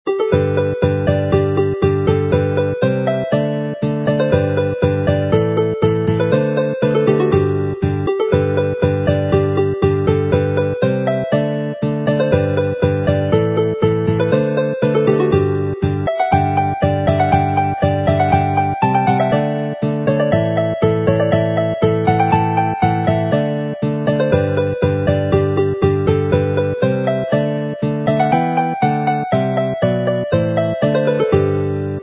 mp3 file as a march, fast with chords